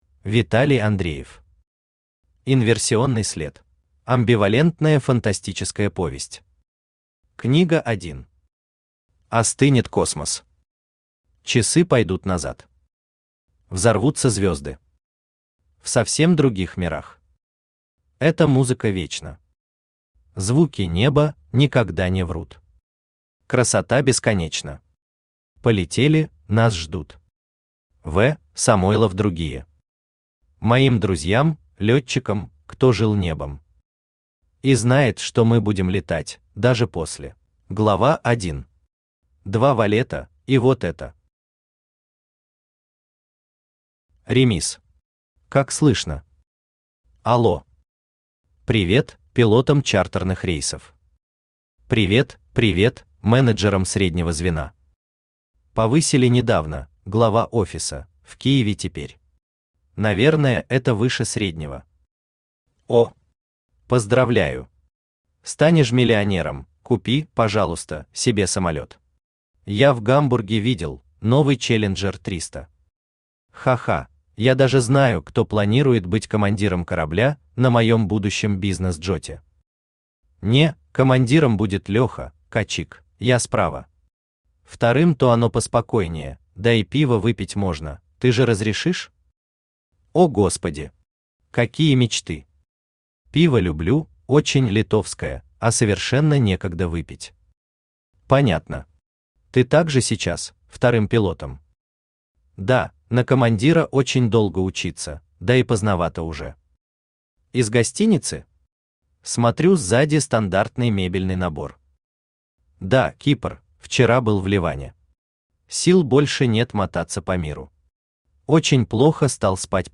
Аудиокнига Инверсионный след | Библиотека аудиокниг
Aудиокнига Инверсионный след Автор Виталий Андреев Читает аудиокнигу Авточтец ЛитРес.